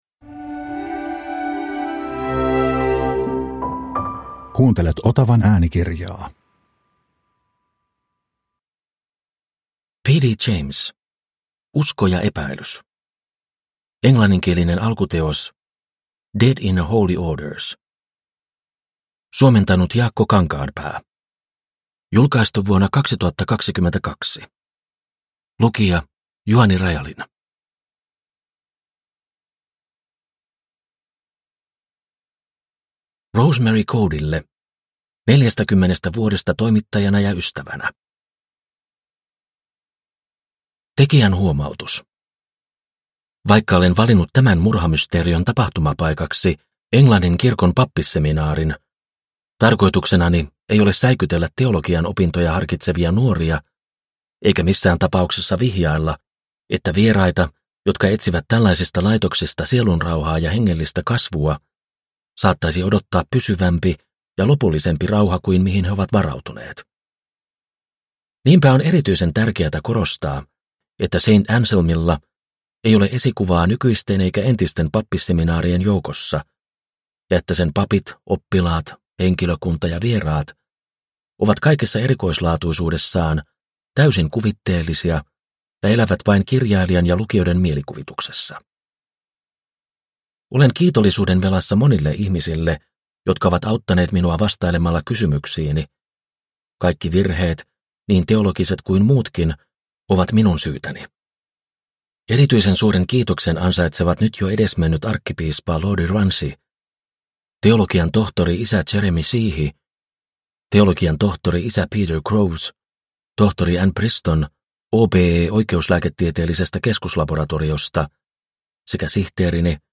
Usko ja epäilys – Ljudbok – Laddas ner
Uppläsare: